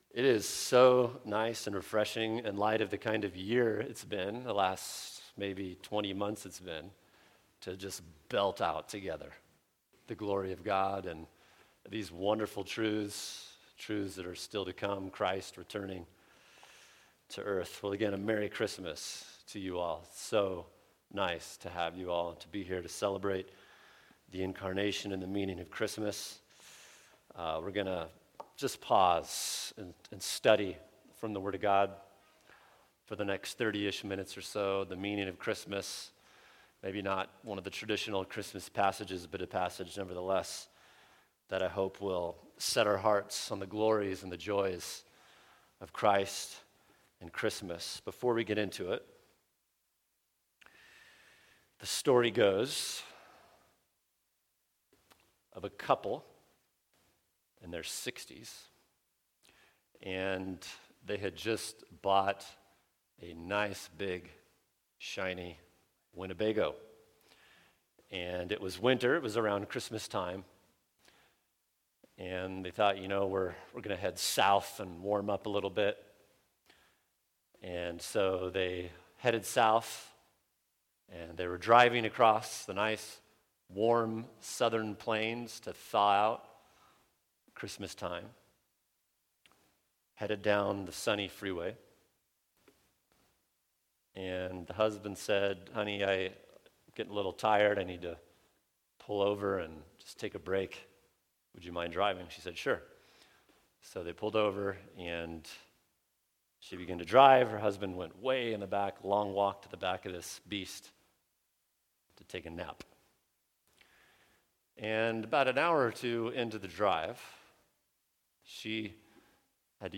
[sermon] John 5:1-24 Why The Manger Is A Big Deal | Cornerstone Church - Jackson Hole